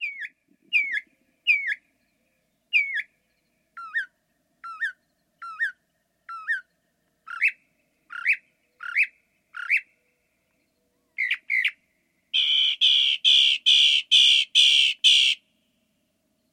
kids-mockingbirdaudio.mp3